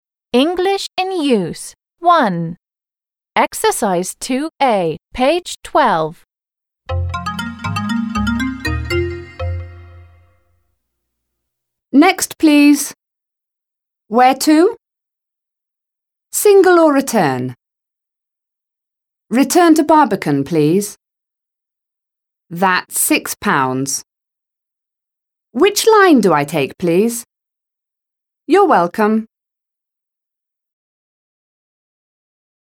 2. a) Listen and repeat. Who says each sentence /phrase: a ticket seller? a passenger?